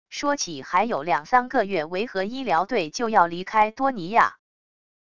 说起还有两三个月维和医疗队就要离开多尼亚wav音频生成系统WAV Audio Player